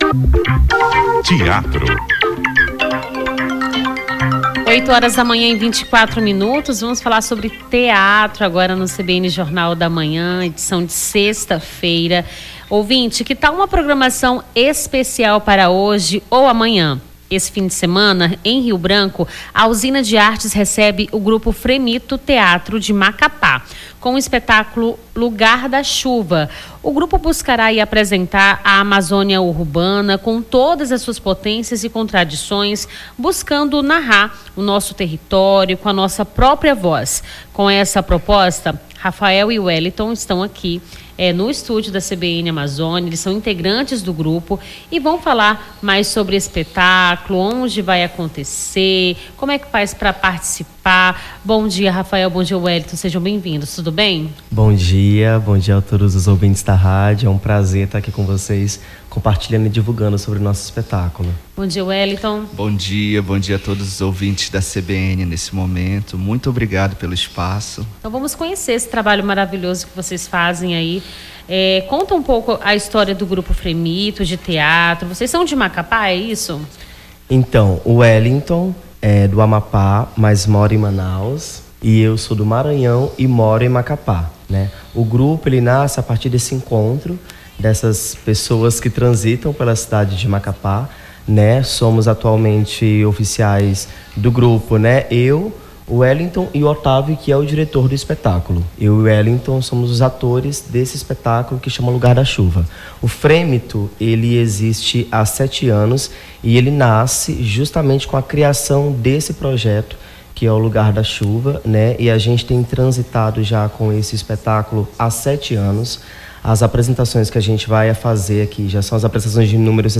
Nome do Artista - CENSURA - ENTREVISTA ESPETÁCULO LUGAR DA CHUVA (21-02-25).mp3